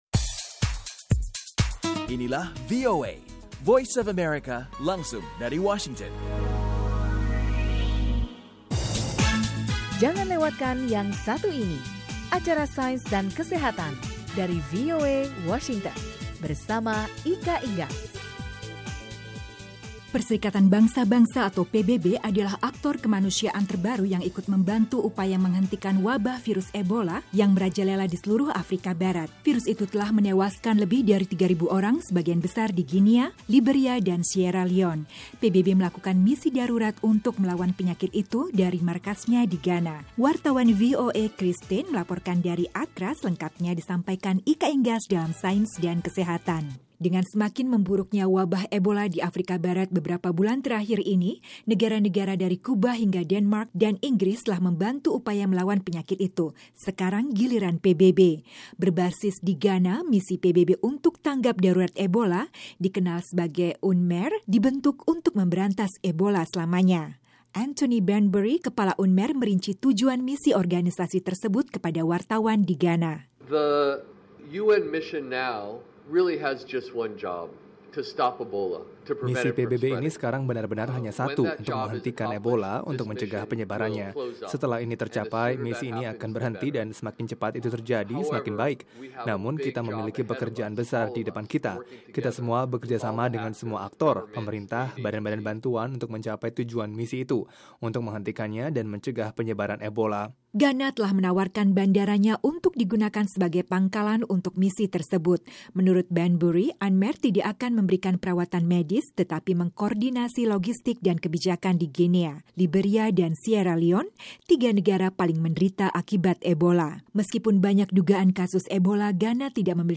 melaporkan dari Accra